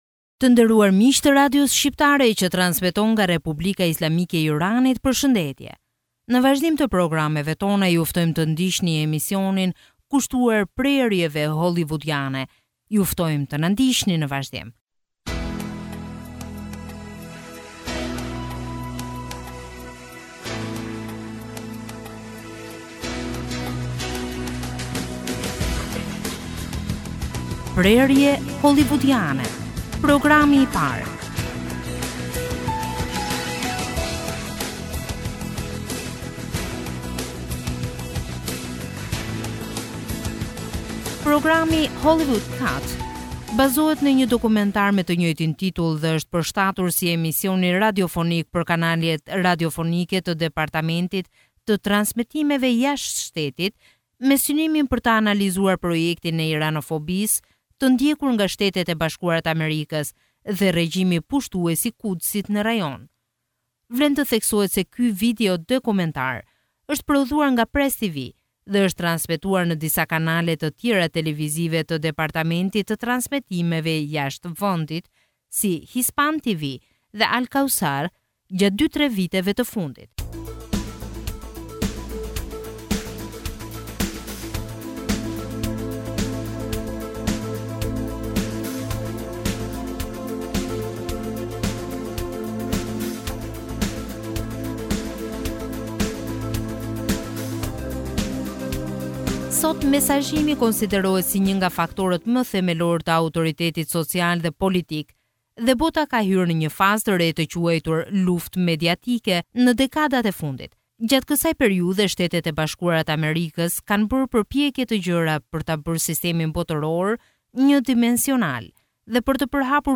Programi "Hollywood Cut" bazohet në një dokumentar me të njëjtin titull dhe është përshtatur si emision radiofonik për kanalet radiofonike të Departamentit të Transmetimeve Jashtë Shtetit, me synimin për të analizuar projektin e Iranfobisë të ndjekur nga SHBA dhe regjimi pushtues i Kudsit në rajon.